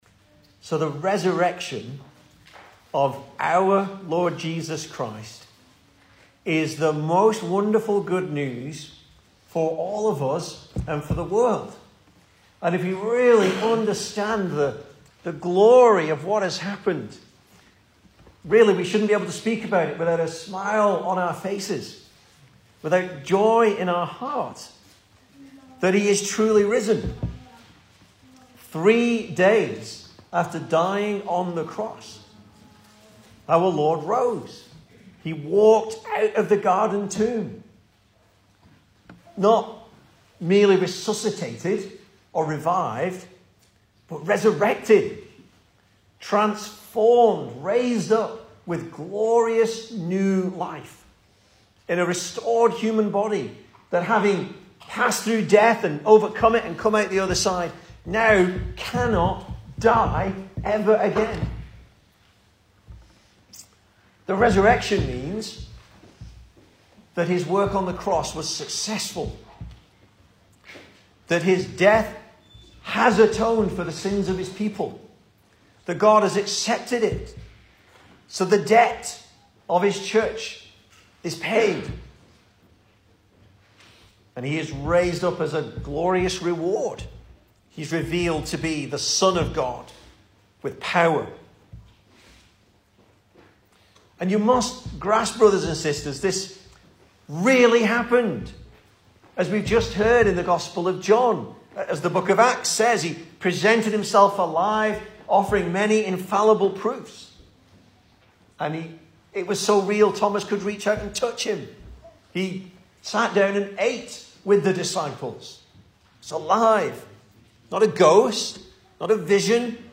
Service Type: Sunday Morning
Easter Sermons